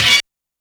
HORN BLAST-L.wav